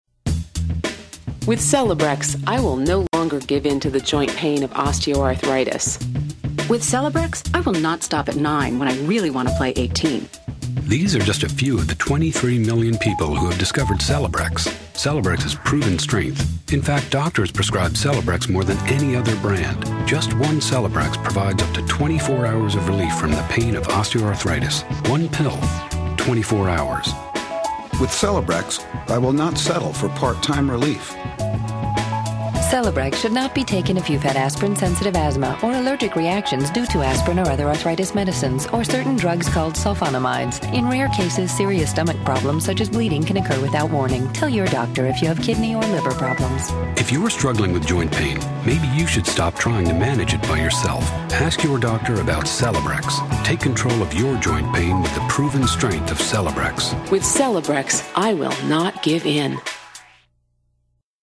background music
use similar drum patterns